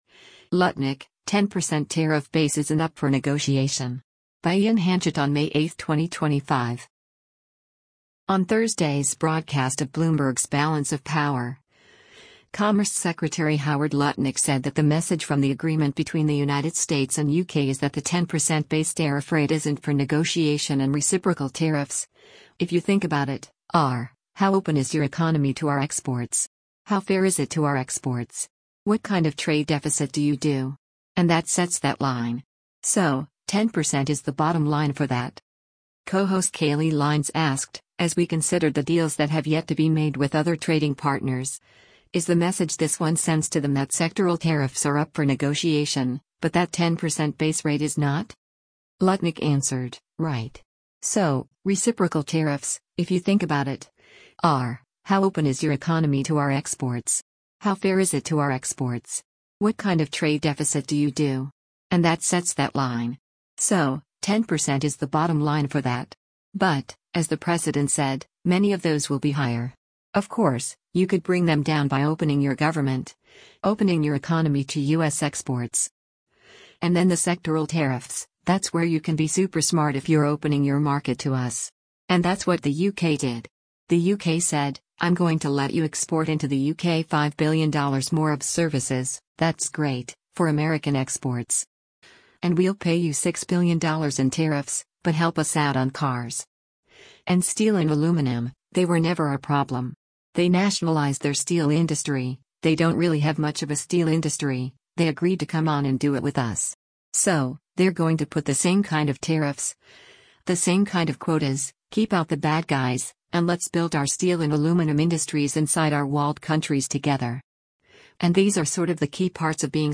On Thursday’s broadcast of Bloomberg’s “Balance of Power,” Commerce Secretary Howard Lutnick said that the message from the agreement between the United States and U.K. is that the 10% base tariff rate isn’t for negotiation and “reciprocal tariffs, if you think about it, [are] how open is your economy to our exports? How fair is it to our exports? What kind of trade deficit do you do? And that sets that line. So, 10% is the bottom line for that.”